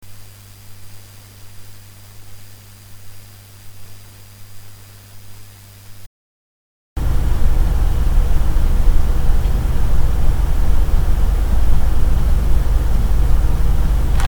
AC駆動，ゲインH，ファンタム48Vで，In2のXLR入力端子にAT4050を接続して
AT4050の接続には1.5mのマイクケーブルを使用．ノイズ音は入らなかった．
1.In1側 (無接続) のノイズ音
2.In2側 (AT4050) の録音内容．部屋の環境音．
AT4050の録音では，部屋は静かだったのに予想以上に周囲の雑音が入ってしまった．